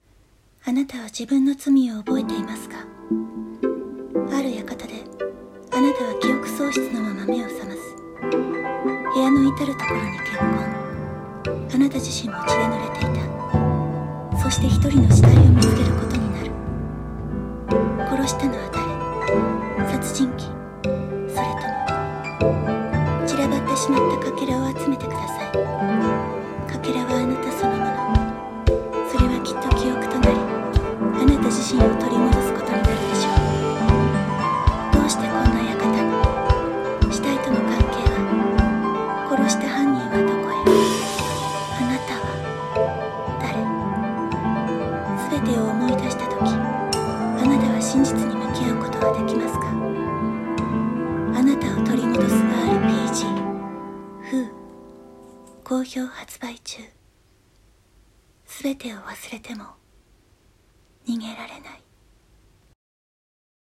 ゲームCM風台本「Who？」